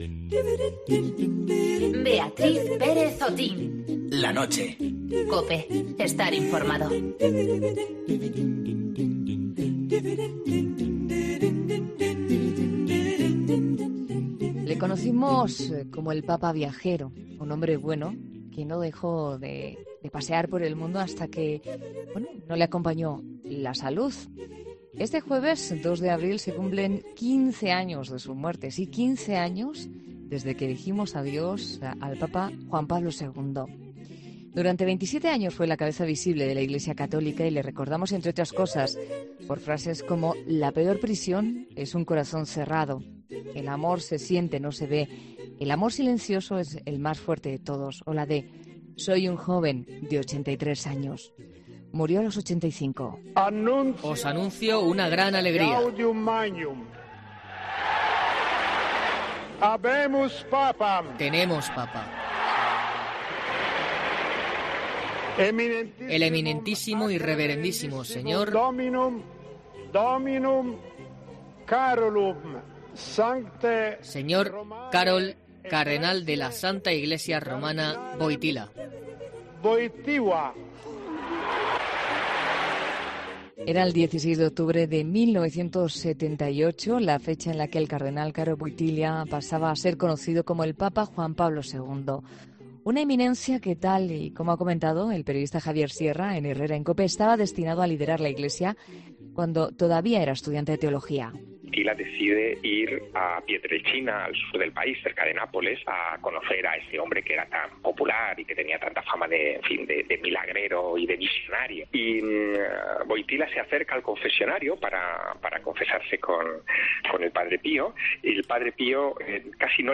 El periodista Javier Sierra los ha contado ante los micrófonos de la Cadena COPE 15 años después de su fallecimiento